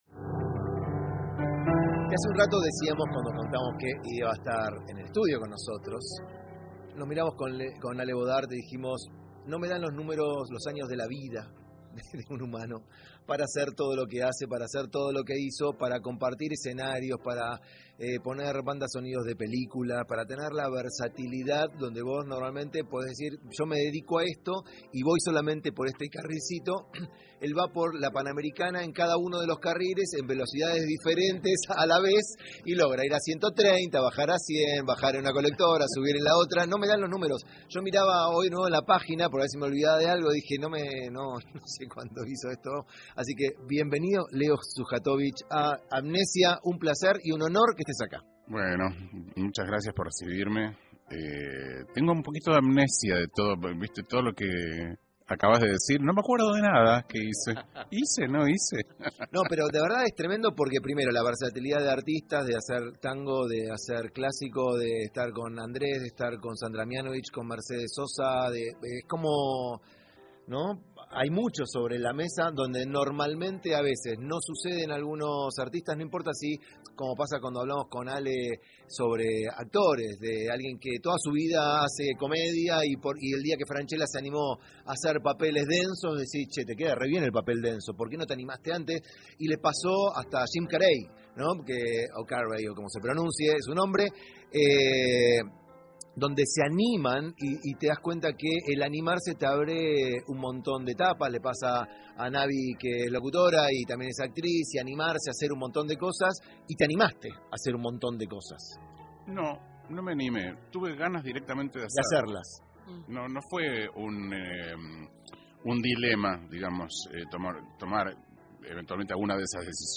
entrevista en amnesia
entrevista-leo-sujatovich.mp3